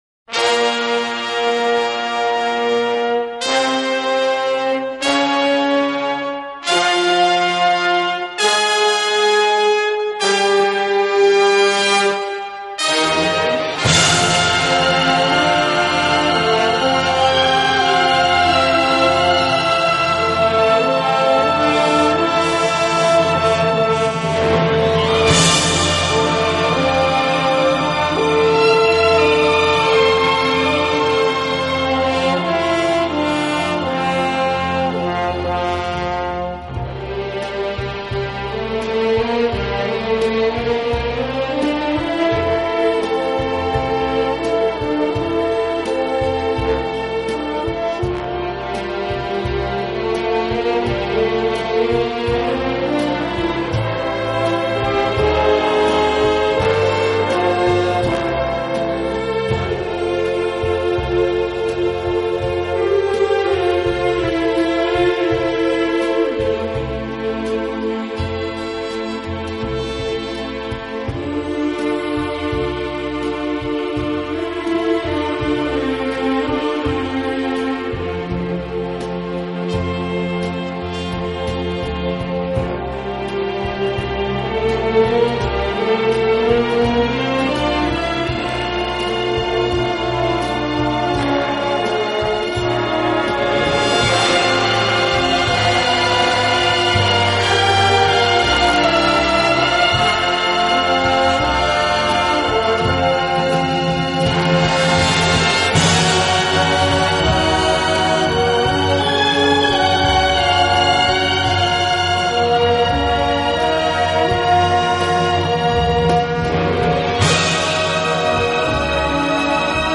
轻音乐专辑